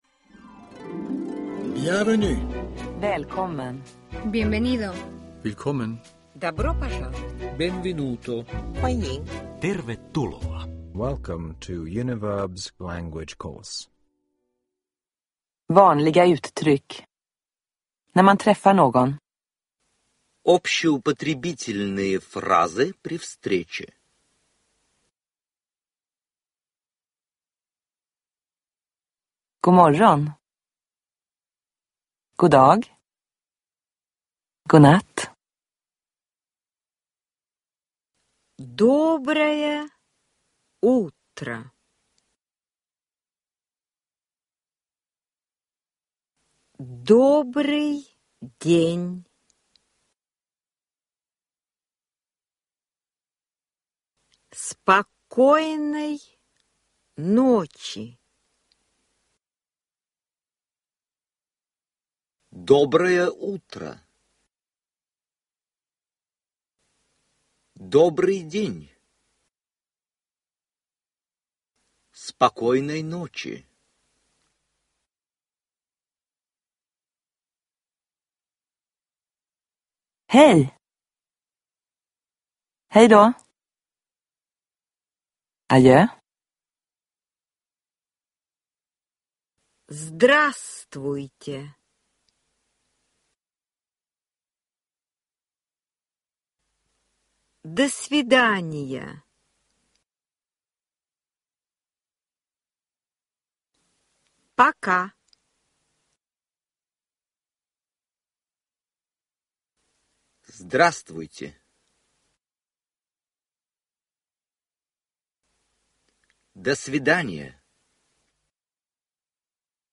Lyssna – Du hör en fras på svenska, därefter två gånger på ryska.
• Ljudbok